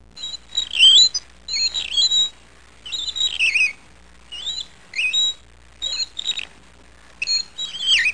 squeaky.mp3